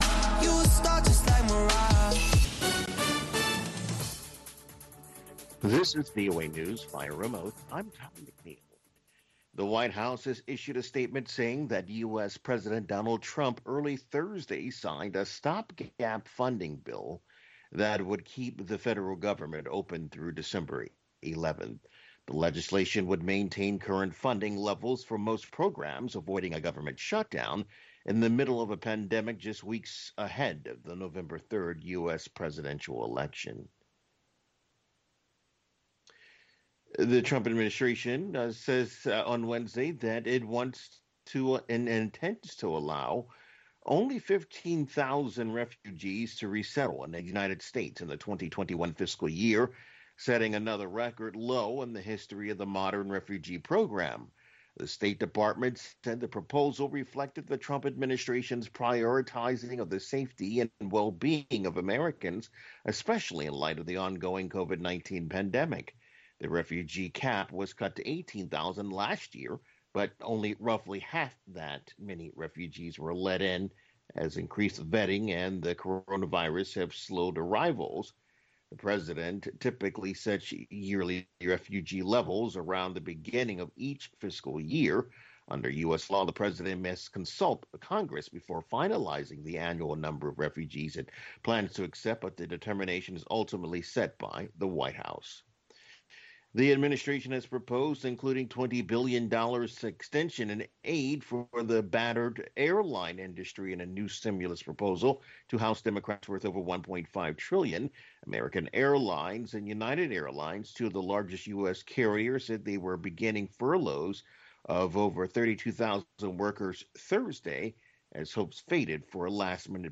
African Beat showcases the latest and the greatest of contemporary African music and conversation. From Benga to Juju, Hip Life to Bongo Flava, Bubu to Soukous and more